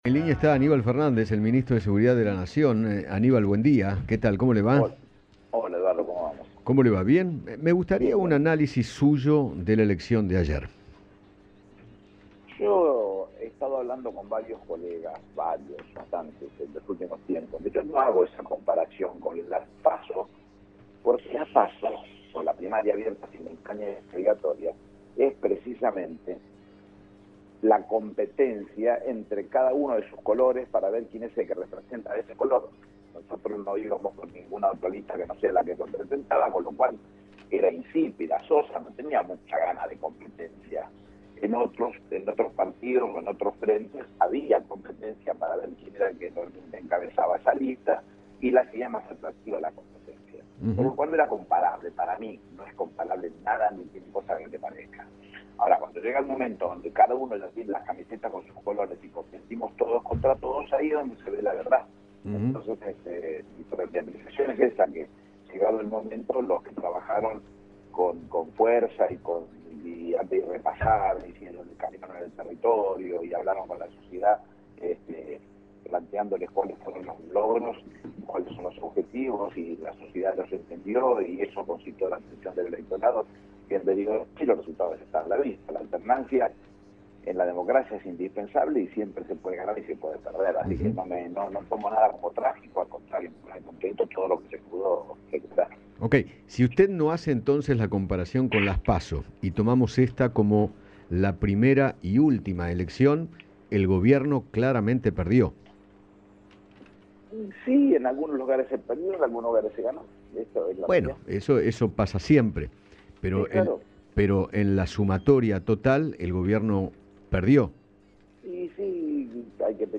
Aníbal Fernández, ministro de Seguridad de la Nación, conversó con Eduardo Feinmann sobre la derrota del Frente de Todos en las elecciones, aunque sostuvo que “en algunos lugares se perdió y en otros se ganó”.